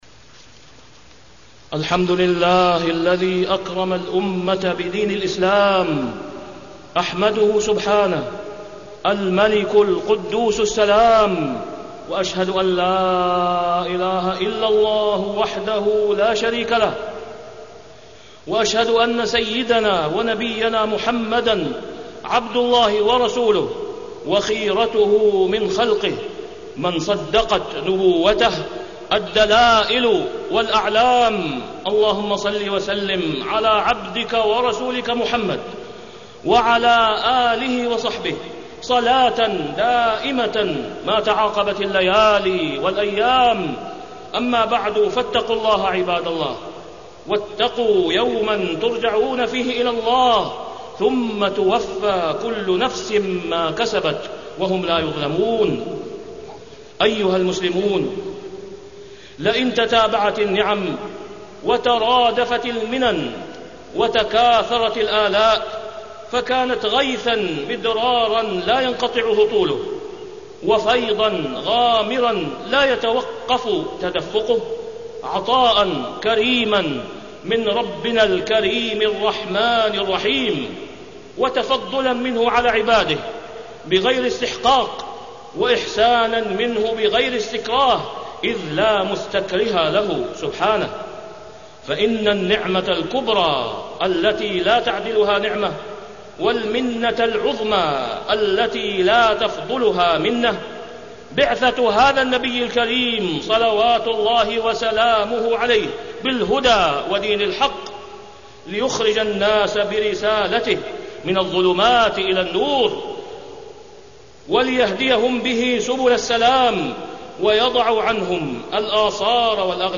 تاريخ النشر ٣ ذو القعدة ١٤٢٧ هـ المكان: المسجد الحرام الشيخ: فضيلة الشيخ د. أسامة بن عبدالله خياط فضيلة الشيخ د. أسامة بن عبدالله خياط شمس الرسالة المحمدية